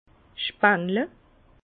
vin_67_p15_spengle_0.mp3 Catégorie Les vendanges Page page 15 Bas Rhin spengle Haut Rhin Français grapiller Ville Bas-Rhin Strasbourg Ville Haut-Rhin Mulhouse Ville Prononciation 67 Herrlisheim Ville Prononciation 68 Munster